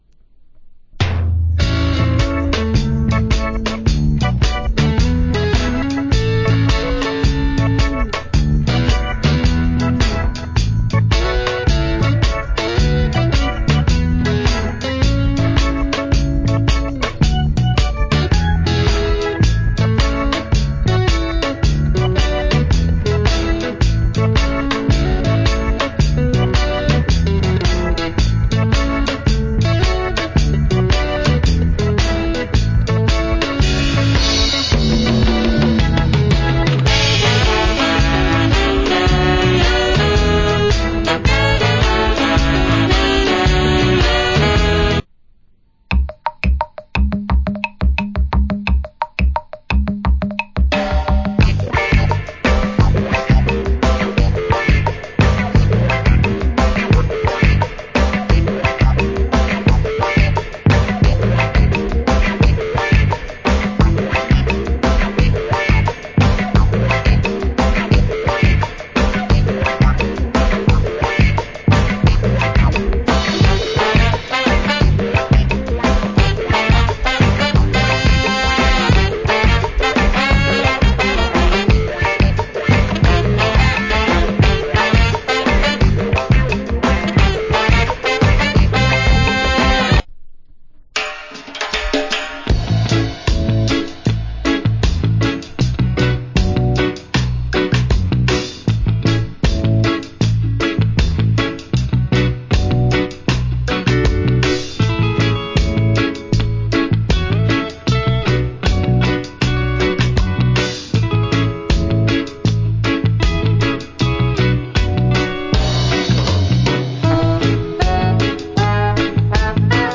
Cool Reggae Album Rep.